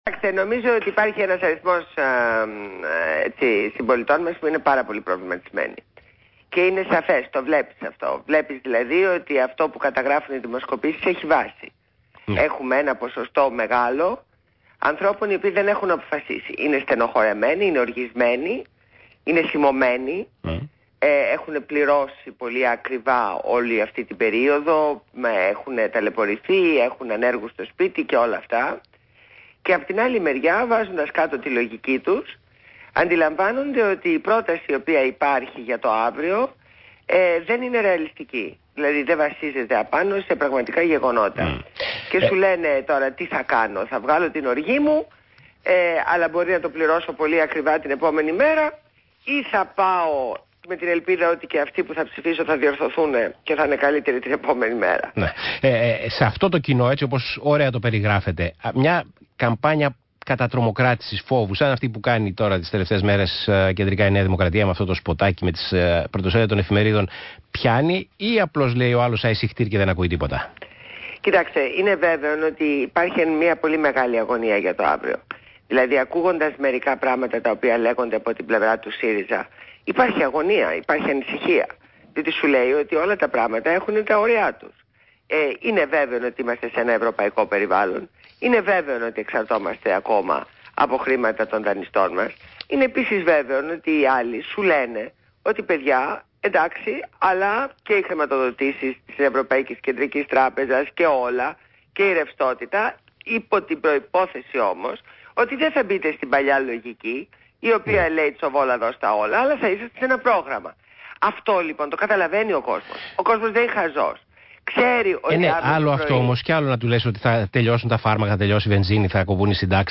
Συνέντευξη στο ΣΚΑΪ, στο δημοσιογράφο Π. Τσίμα